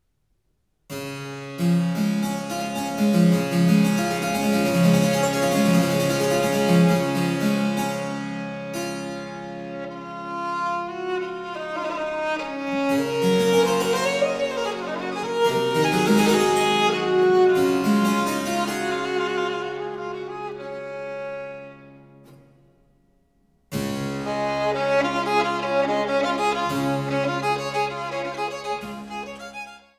Die wohl bekanntesten Violinsonaten des 18. Jahrhunderts